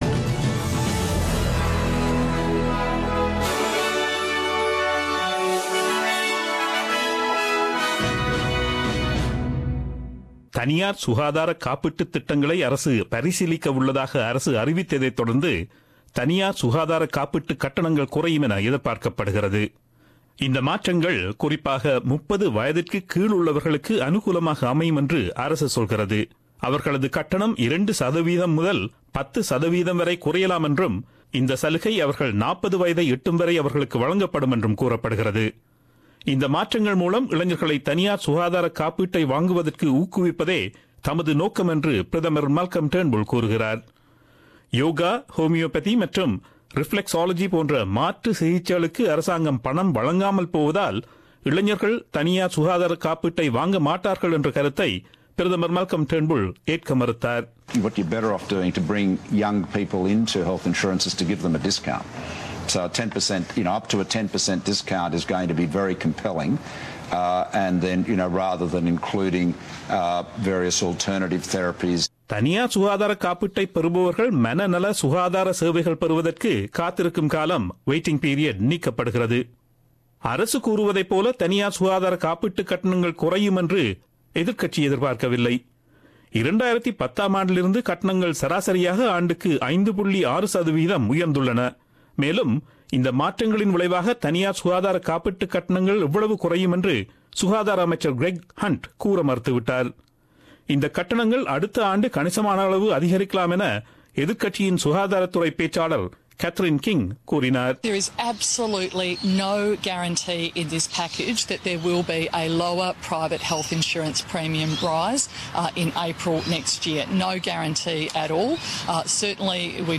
Australian news bulletin aired on Friday 13 October 2017 at 8pm.